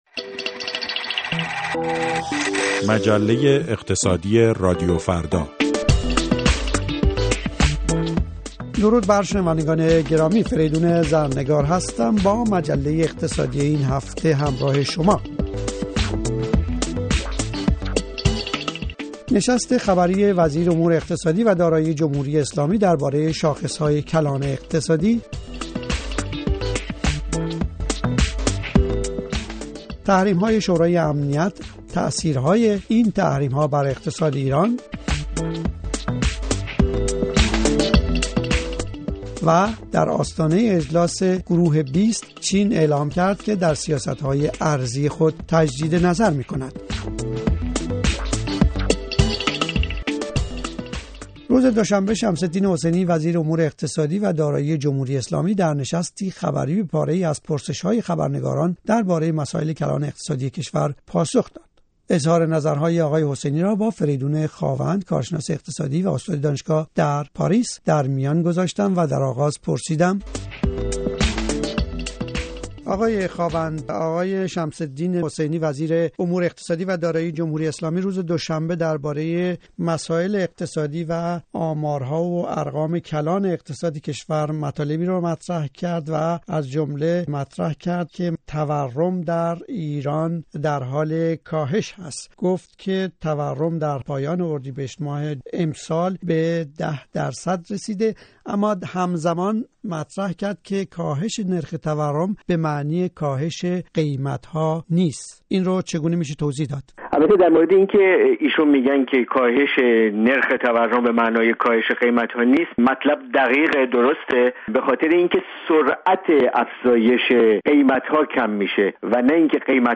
مجله اقتصادی: گفت وگو